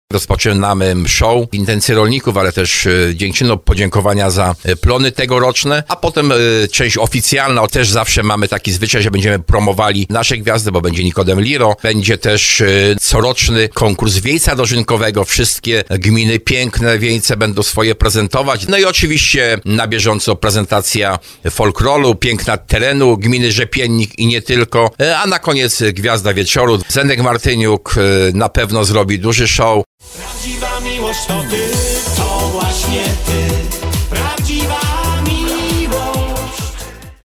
mówi starosta tarnowski Jacek Hudyma